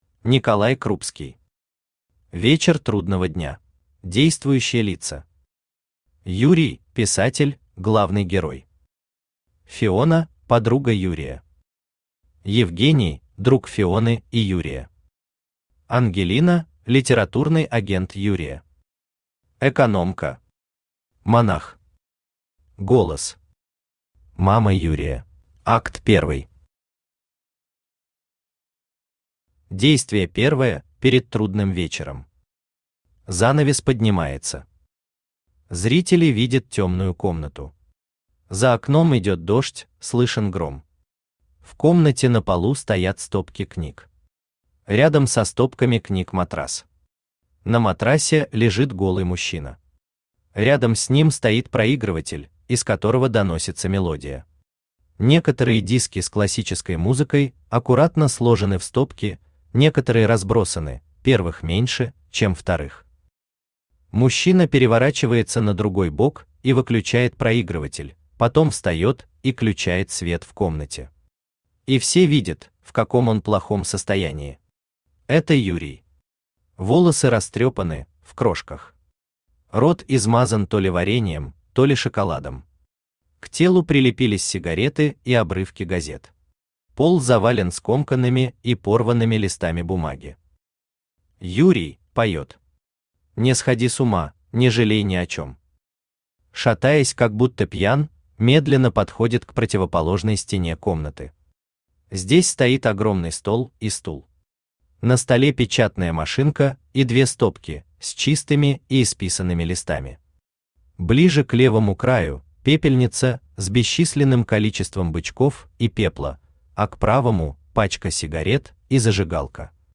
Аудиокнига Вечер трудного дня | Библиотека аудиокниг
Aудиокнига Вечер трудного дня Автор Николай Николаевич Крупский Читает аудиокнигу Авточтец ЛитРес.